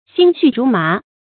心绪如麻 xīn xù rú má 成语解释 犹心乱如麻。
成语繁体 心緒如麻 成语简拼 xxrm 成语注音 ㄒㄧㄣ ㄒㄩˋ ㄖㄨˊ ㄇㄚˊ 常用程度 一般成语 感情色彩 中性成语 成语用法 作谓语、定语、状语；形容心里非常烦 成语结构 偏正式成语 产生年代 近代成语 近 义 词 心乱如麻 成语例子 此刻记了这个，忘了那个；及至想起那个，又忘了这个；真是心绪如麻，何能再说笑话？